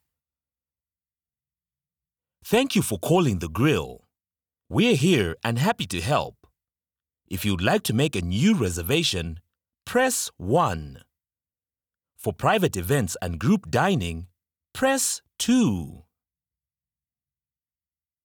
Inglés (África)
IVR
Sennheiser MKH 416
ProfundoBajo